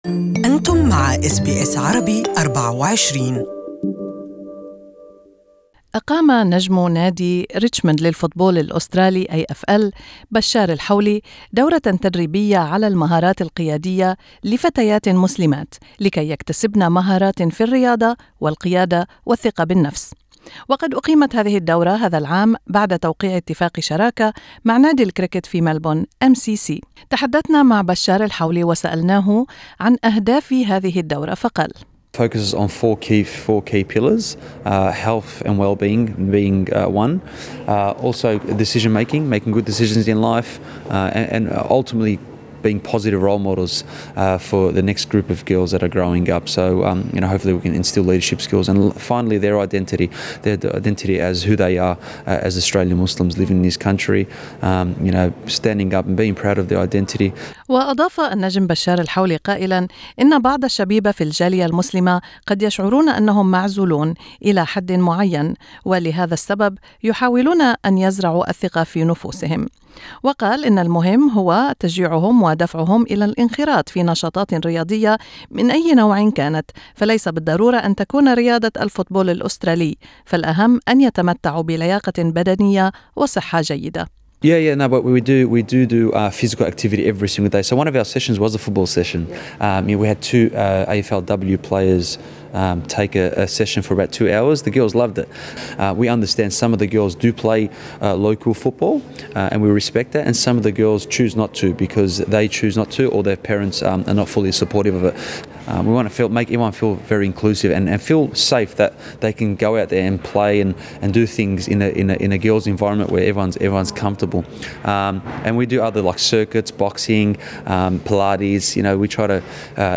Source: SBS استمعوا إلى لقاءات مع بشار الحولي ومع عدد من الفتيات تحت الشريط الصوتي في أعلى الصفحة.